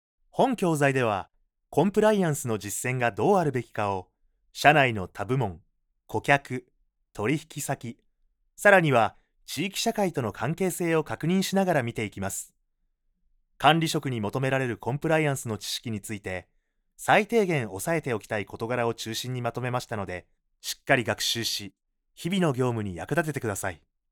落ち着いたトーンからハリのある元気な声まで、ご要望に合わせます。
関西弁を話せます。
– ナレーション –
eラーニング